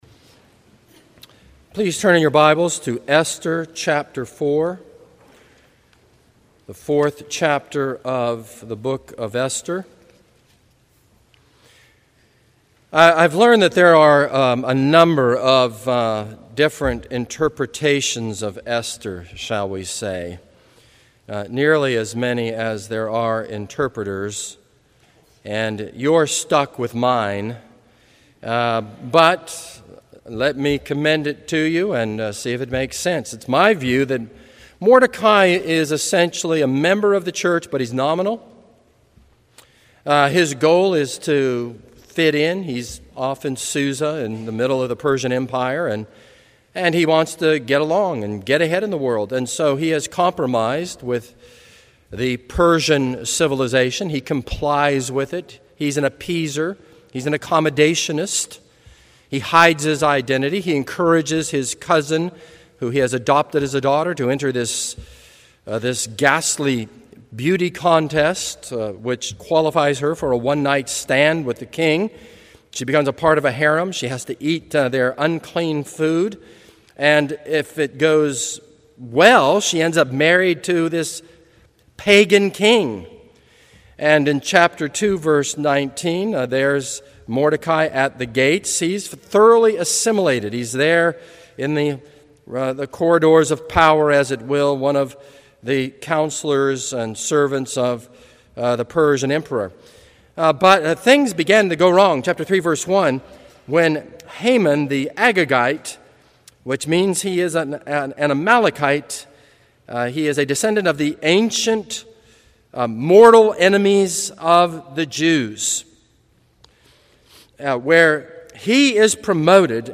This is a sermon on Esther 4.